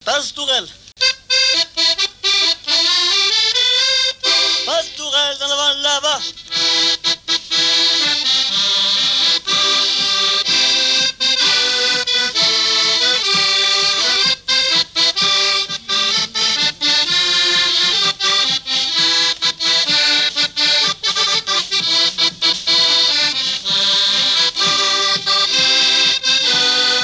Quadrille - Pastourelle
Saint-Pierre-du-Chemin
danse : quadrille : pastourelle
Pièce musicale inédite